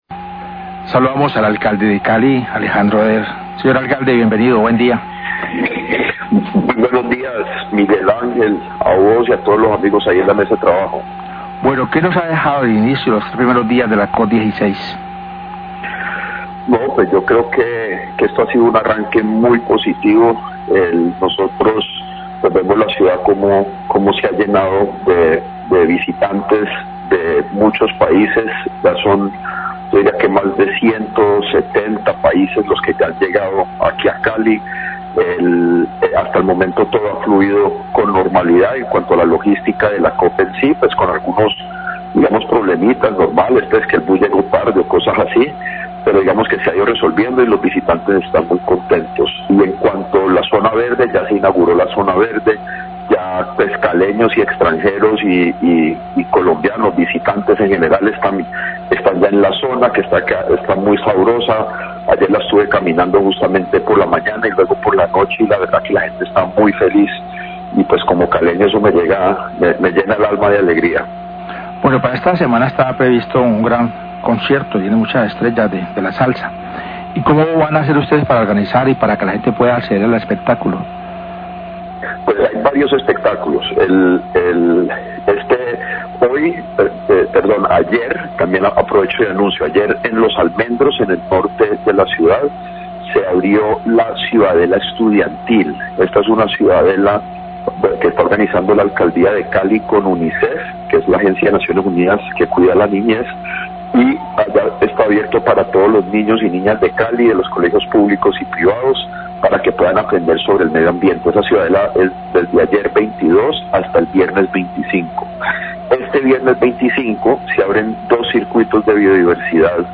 Entrevista al alcalde de Cali, Alejandro Eder, sobre los primeros días de la COP16 en la ciudad. Se refirió al desarrollo de la zona verde, la ciudadela estudiantil, los cirtcuitos de biodiversidad y al concierto 'paz con la naturaleza' que se realizará el fin de semana.